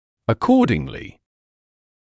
[ə’kɔːdɪŋlɪ][э’коːдинли]соответственно; таким образом; следовательно